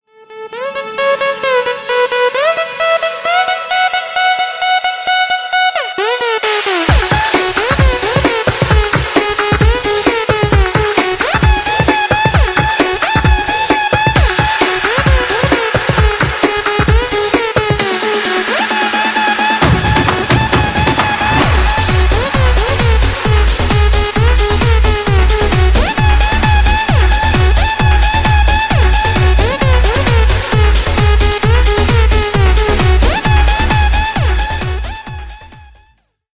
Ремикс
клубные # без слов